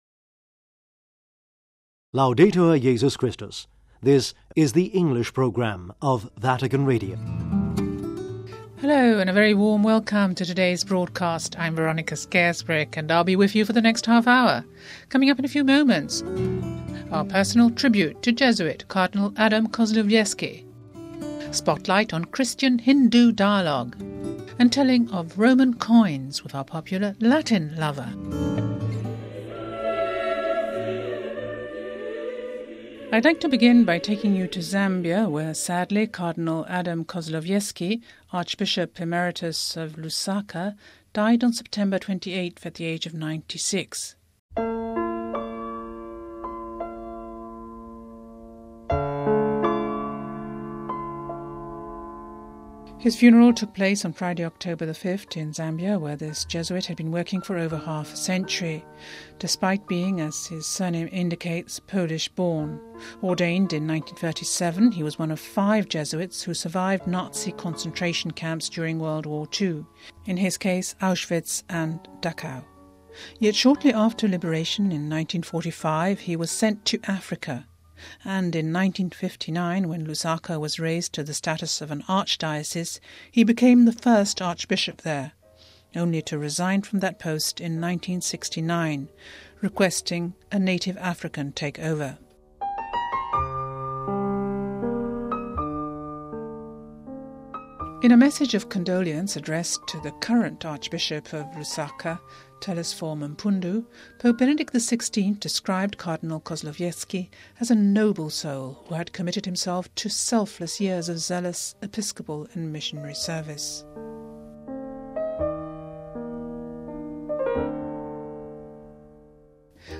CHRISTIAN AND HINDU S - A Christian from the Focolari Catholic lay movement and a Hindu from the Ghandian inspired Shanti Ashram speak to us of dialogue.